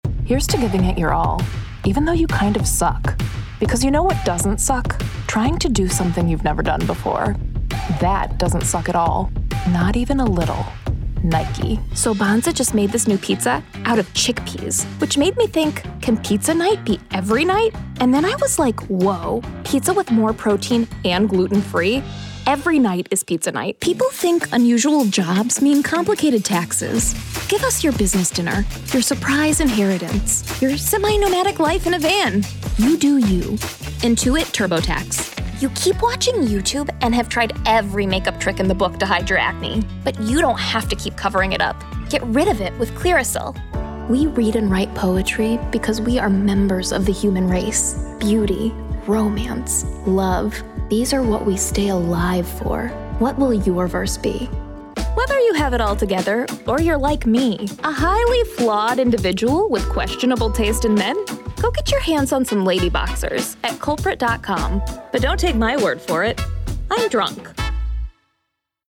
Voiceover Artist,
Sex: Female
Accents: US Midwest, US General American
Sennheiser MKH 416 microphone, Studiobricks One Plus VO Edition, Source Connect